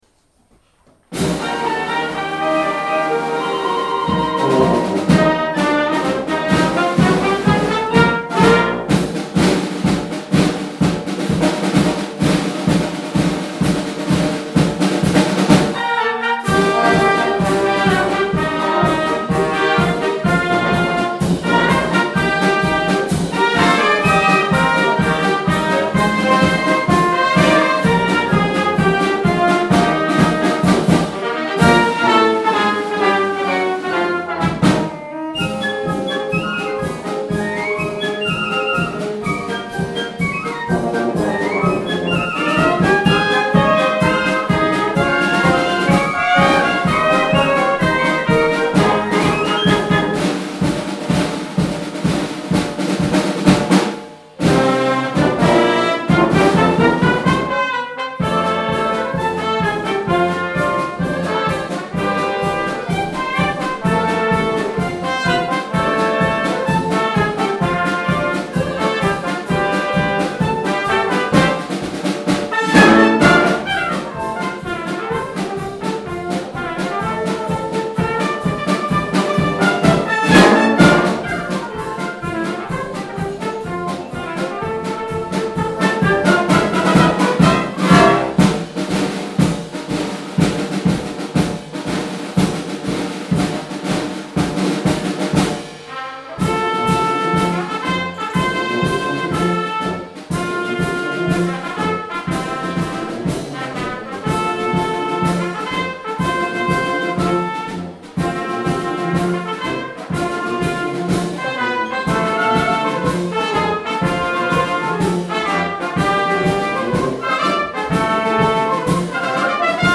教会に響く吹奏楽 PART2〜白樺学園高校吹奏楽部 帯広聖公会礼拝堂コンサートより
教会で演奏する生徒達。